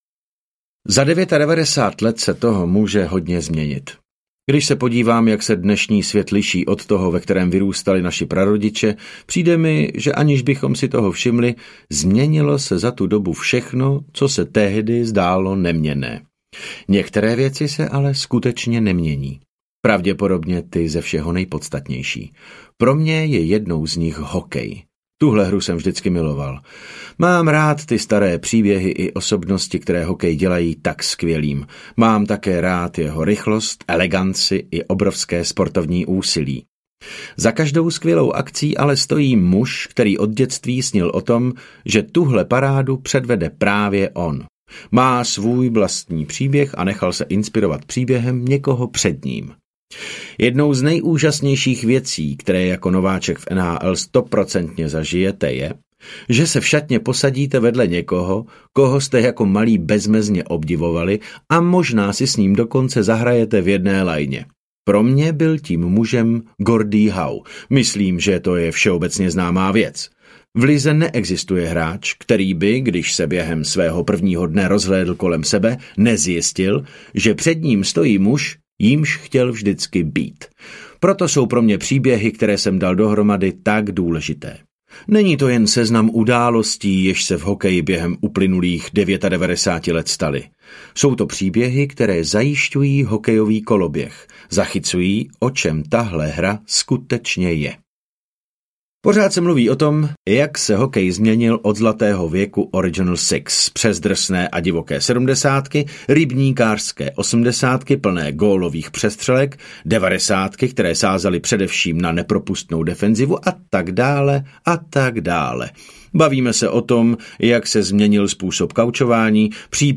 99: Hokejové příběhy audiokniha
Ukázka z knihy
• InterpretDavid Novotný